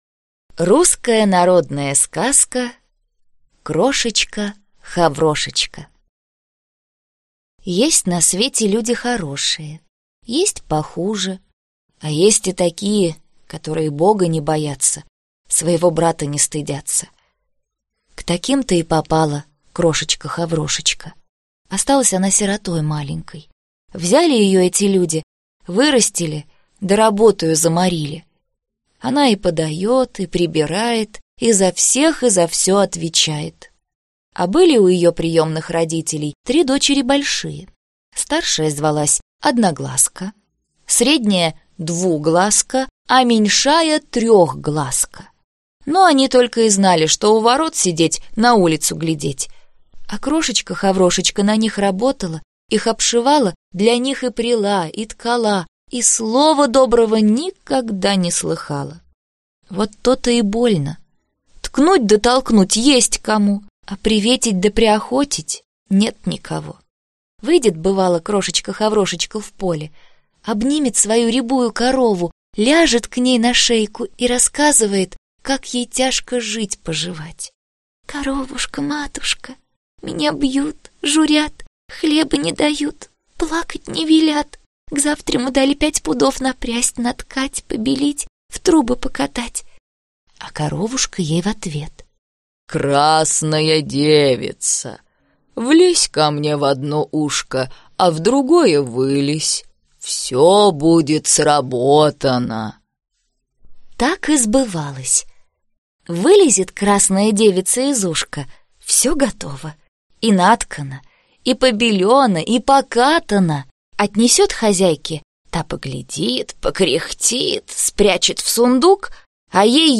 Аудиокнига Волшебные сказки | Библиотека аудиокниг